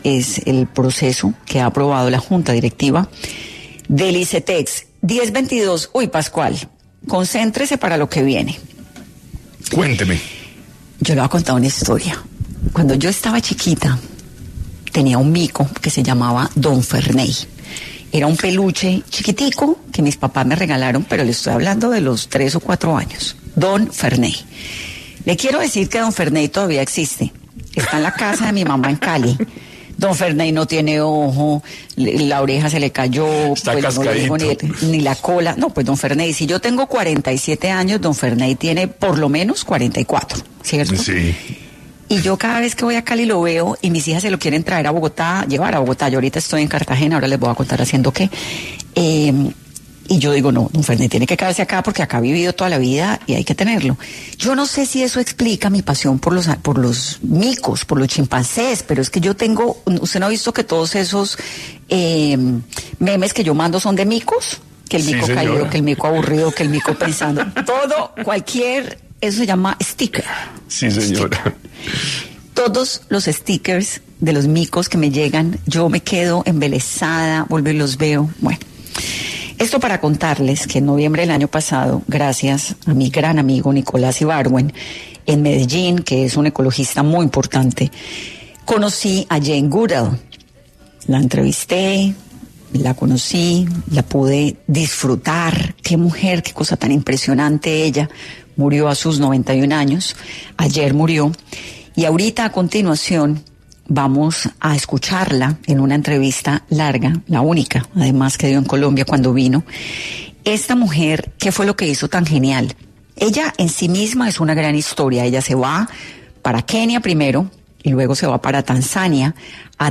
En entrevista inédita hecha en 2024 con Vanessa de la Torre, la etóloga recoge sus aprendizajes con los chimpancés que trabajó.